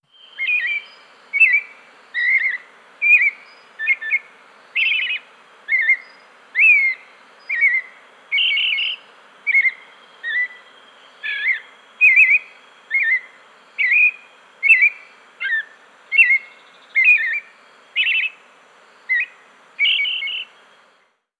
Birds
ForrestBird2.wav